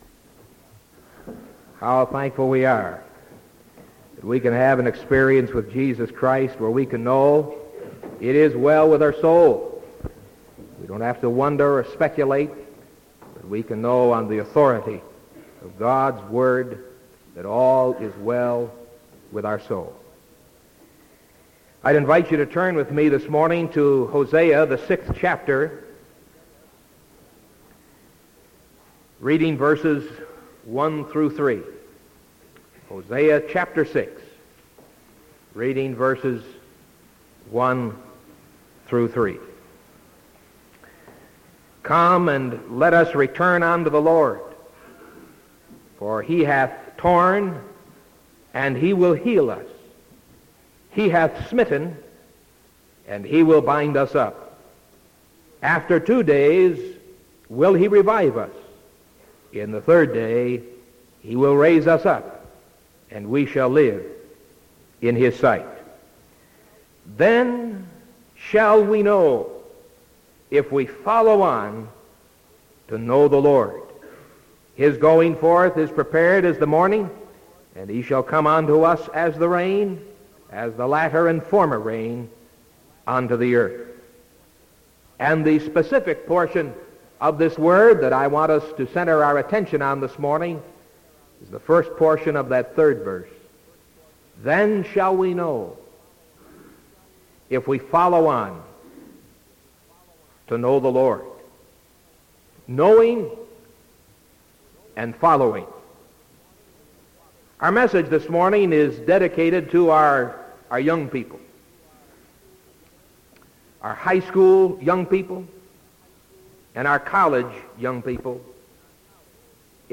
Sermon April 27th 1975 AM